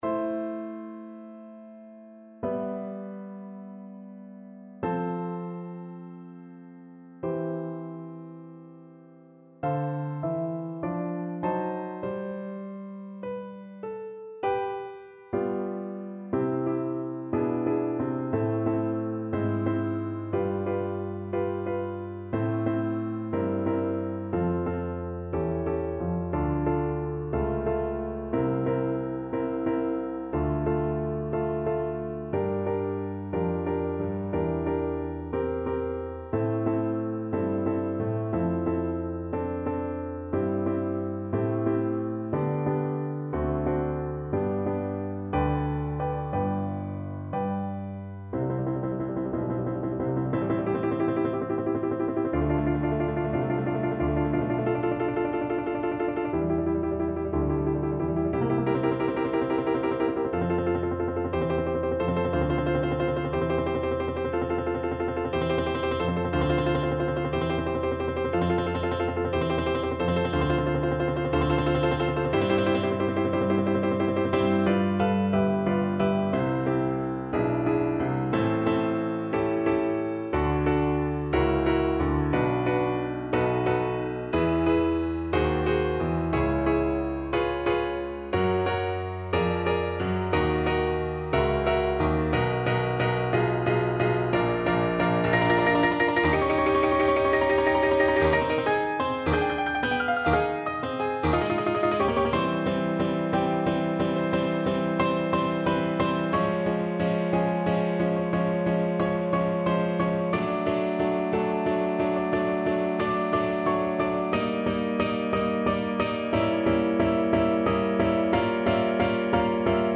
Moderato assai
4/4 (View more 4/4 Music)
G5-Db7
Classical (View more Classical Alto Recorder Music)